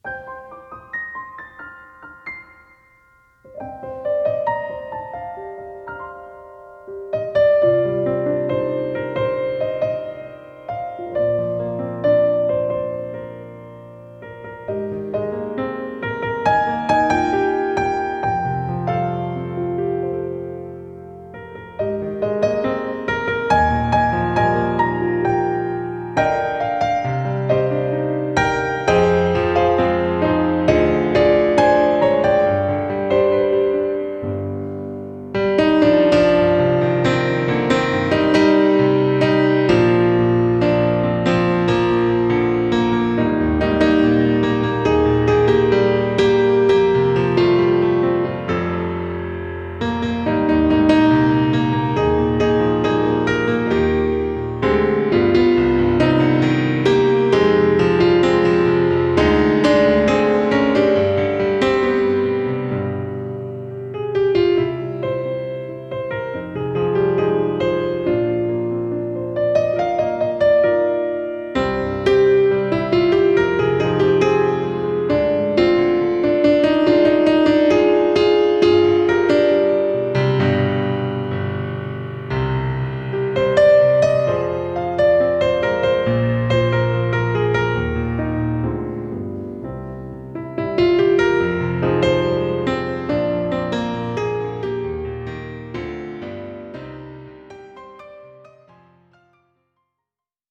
Dramatic inst)　Piano